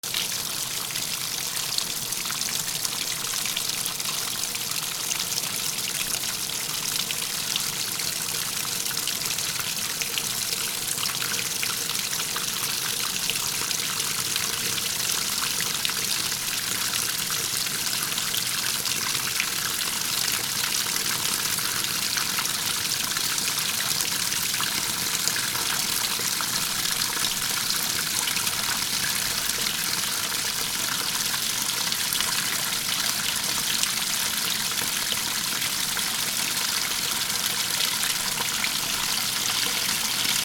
/ M｜他分類 / L30 ｜水音-その他
洗濯機 4 注水
『パシャシャ』